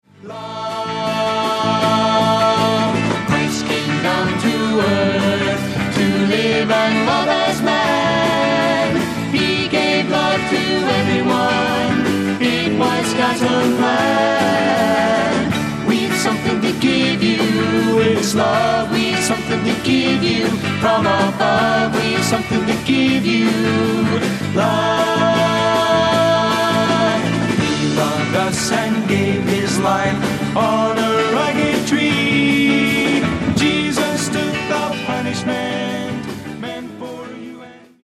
SOFT ROCK / GARAGE